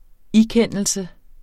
Udtale [ ˈiˌkεnˀəlsə ]